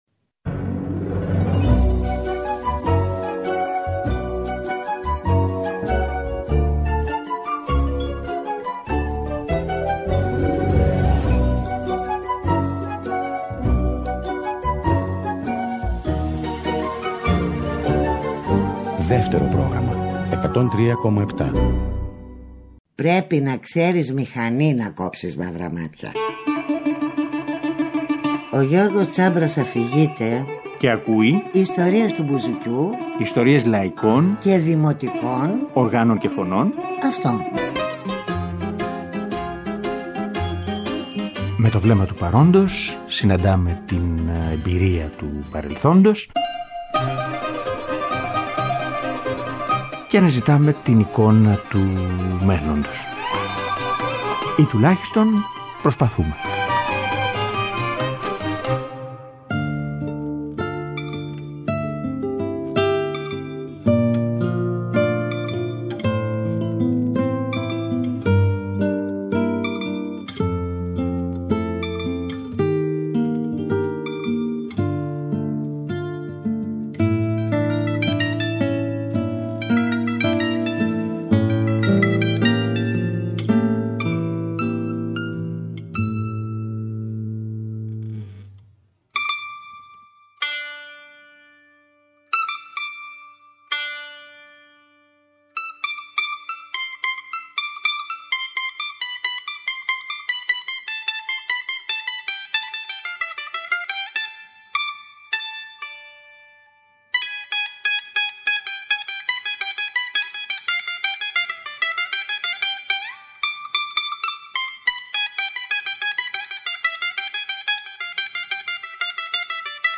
Τραγούδια